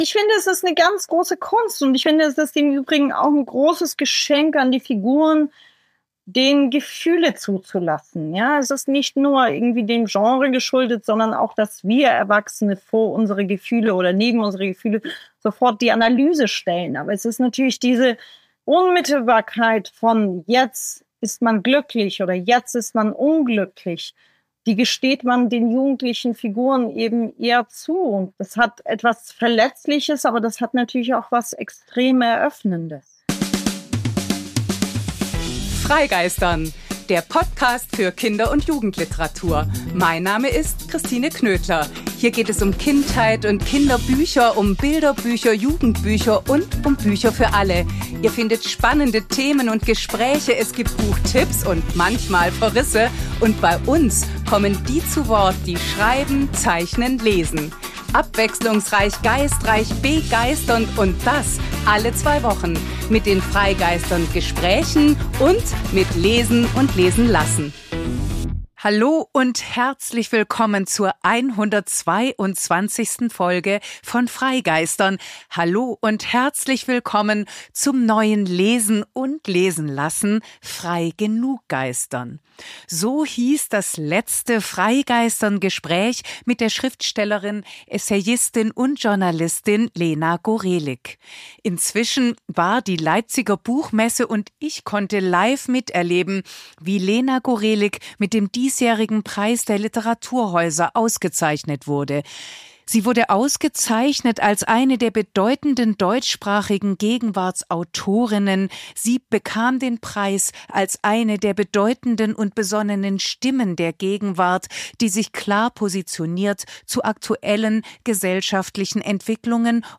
Darum gibt es in diesem neuen „Lesen und lesen lassen“ einen kleinen Messe-Rückblick, und weil außerdem Ostern vor der Tür steht, kommt ein bunter Strauß an Buch-Tipps fürs Osternest oder Osterkörbchen obendrauf: Geschichten mit Hühnern, Hasen und anderem Getier, eine Gedicht-Schatz-Kiste, außerdem ein betörendes Sachbuch, das passenderweise das Schwärmen im Titel trägt. Zum krönenden Abschluss liest Lena Gorelik in der Rubrik „Vorlesen!“ den Anfang ihres Romans „Alle meine Mütter“: mehr „freigenuggeistern“ geht nicht!